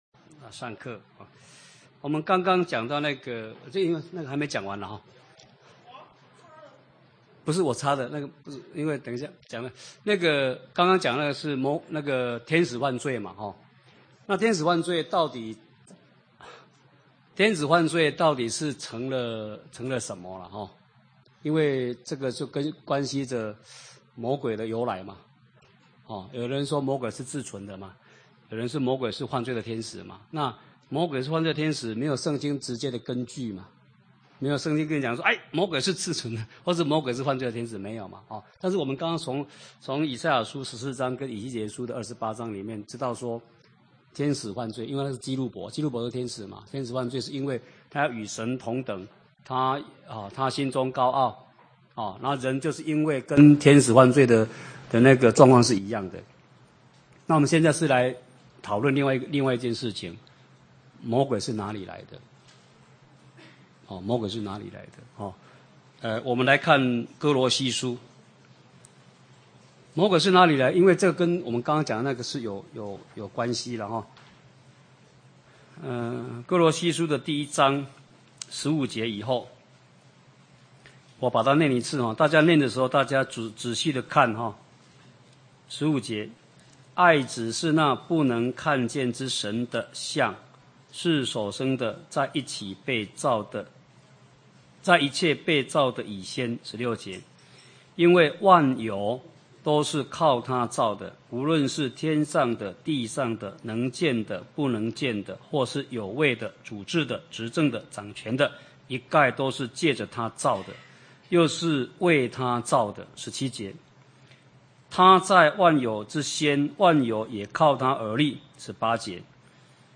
講習會
地點 台灣總會 日期 02/17/2011 檔案下載 列印本頁 分享好友 意見反應 Series more » • 耶利米書44-01：緒論(1